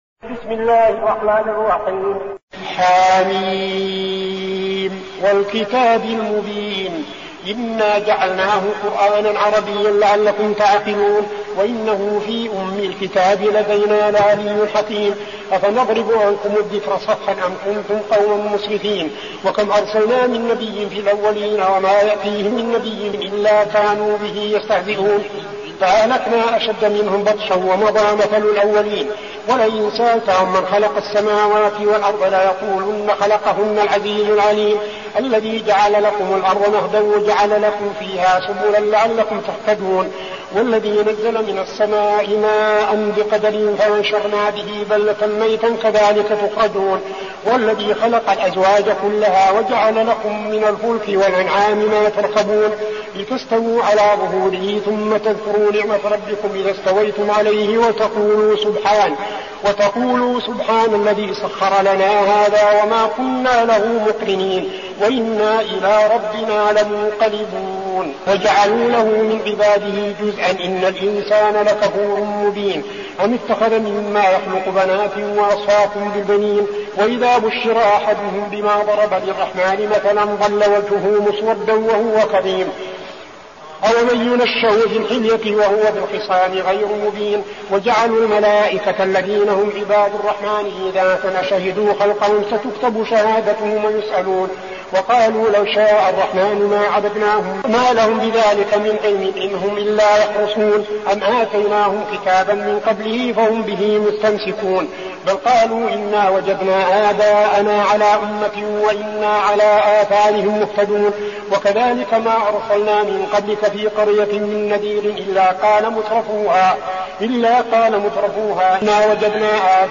المكان: المسجد النبوي الشيخ: فضيلة الشيخ عبدالعزيز بن صالح فضيلة الشيخ عبدالعزيز بن صالح الزخرف